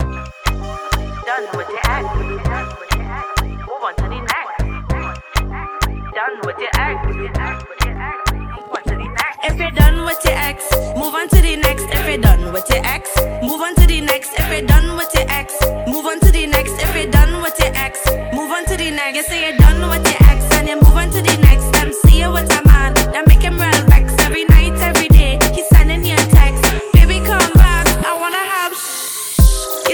Скачать припев
Off-beat гитары и расслабленный ритм
Modern Dancehall Reggae African Afro-Beat
2025-05-08 Жанр: Регги Длительность